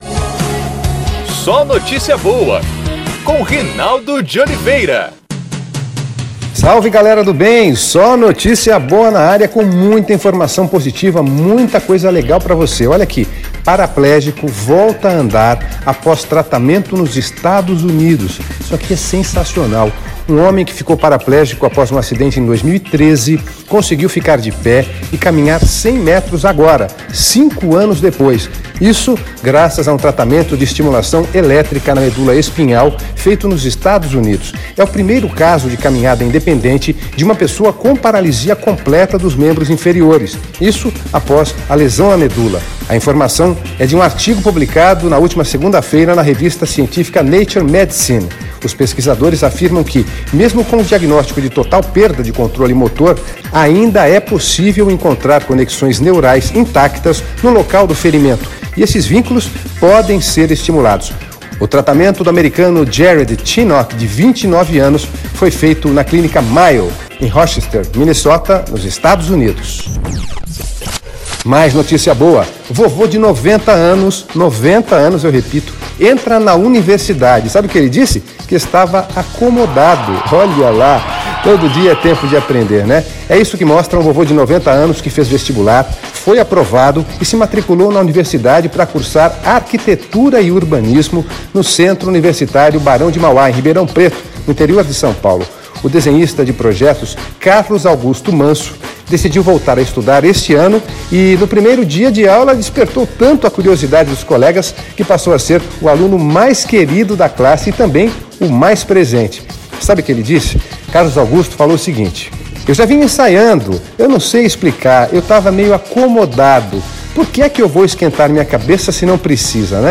É o podcast SóNotíciaBoa, nosso programa de rádio.